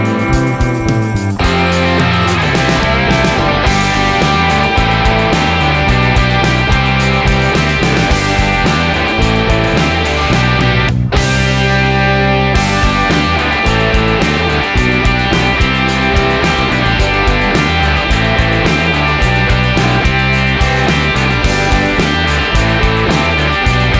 Backing tracks for male or boy singing parts.